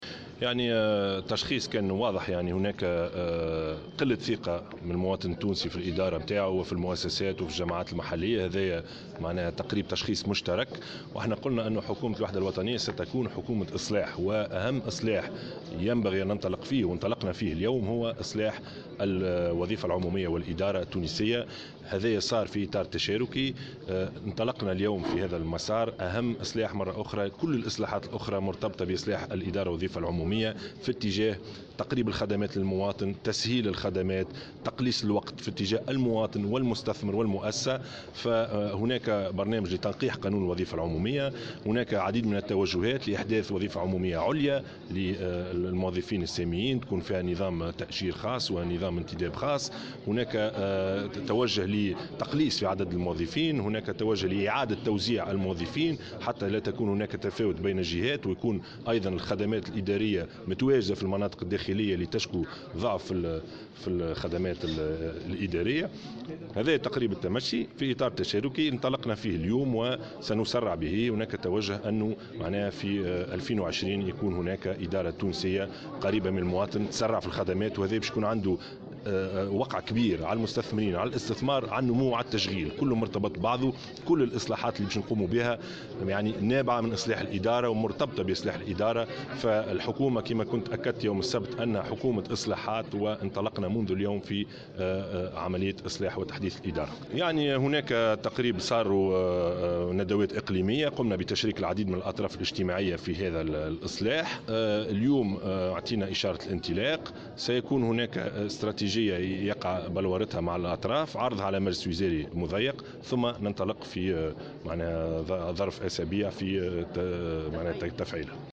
أكد رئيس الحكومة يوسف الشاهد في تصريح اعلامي صباح اليوم الإثنين 16 جانفي 2016 أن التشخيص للإدارات العمومية توصل إلى قلة ثقة المواطن في الإدارة والجماعات المحلية .